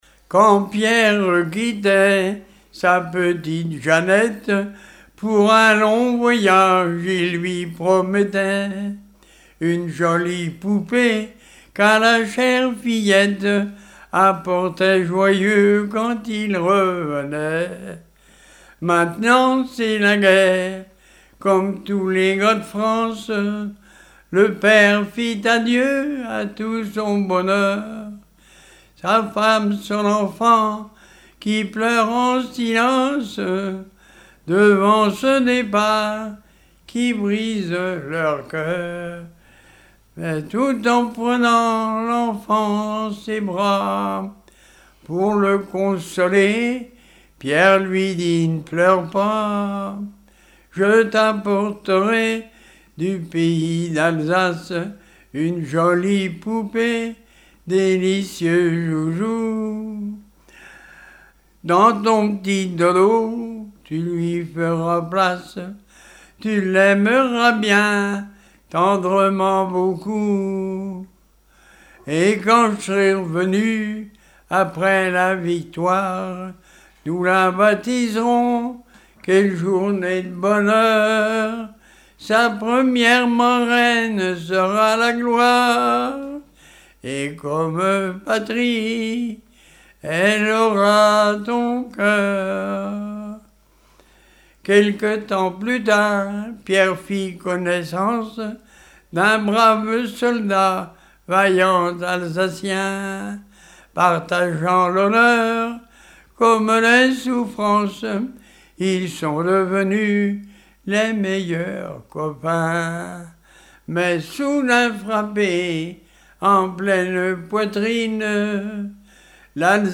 Genre strophique
Chansons et témoignages
Pièce musicale inédite